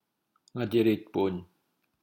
IPA= ŋaciricbun